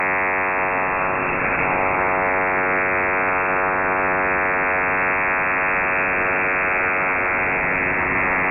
Fmcw_96Hz_1usb.mp3